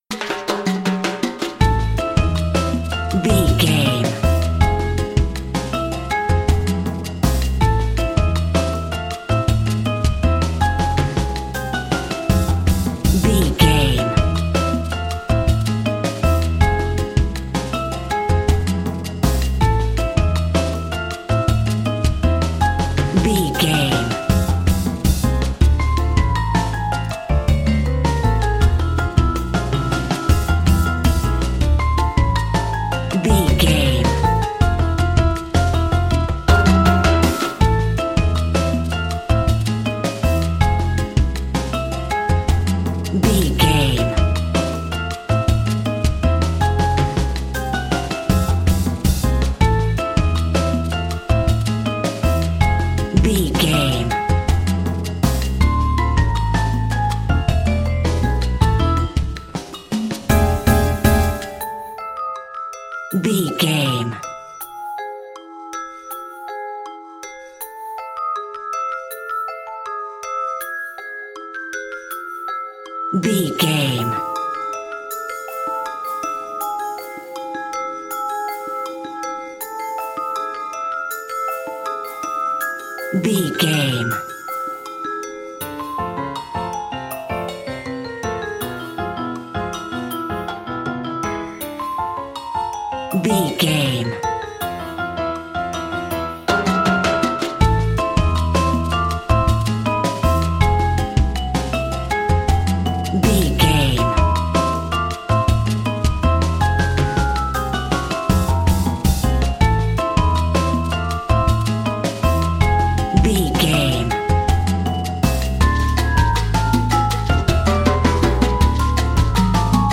Aeolian/Minor
percussion
silly
circus
goofy
comical
cheerful
perky
Light hearted
quirky